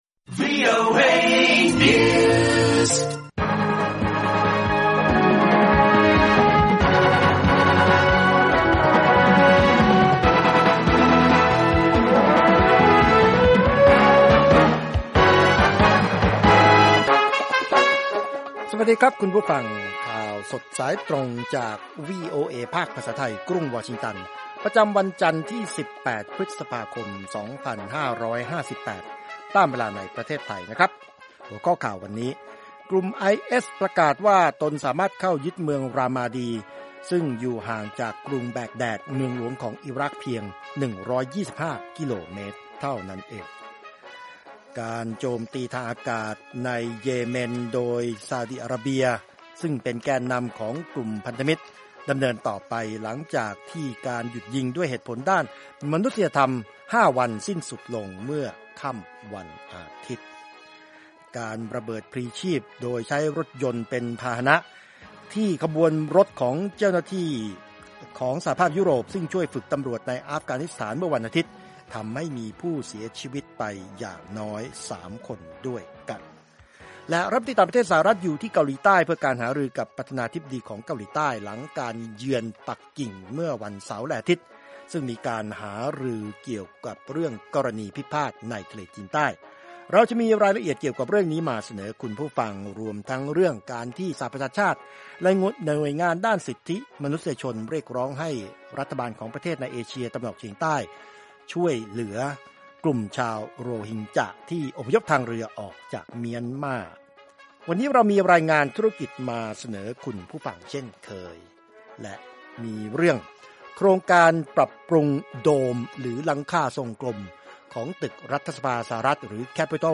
ข่าวสดสายตรงจากวีโอเอ ภาคภาษาไทย 8:30–9:00 น. วันจันทร์ที่ 18 พฤษภาคม 2558